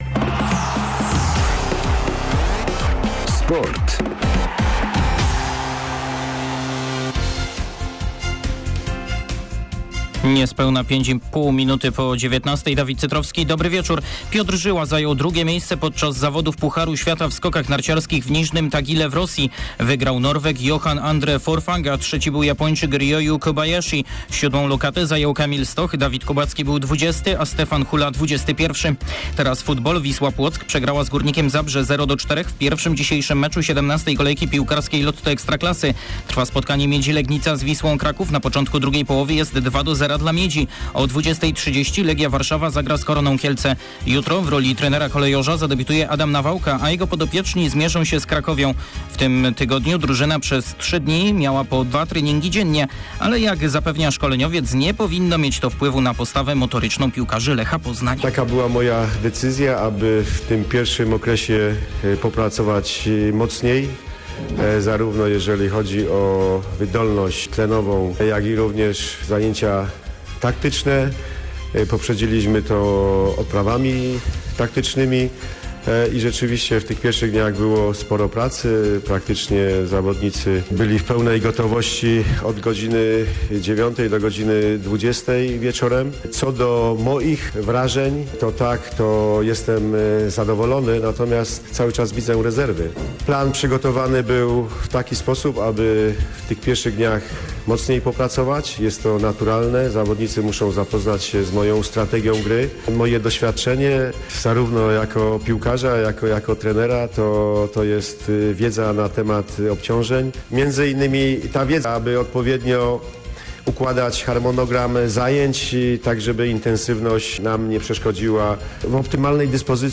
01.12. serwis sportowy godz. 19:05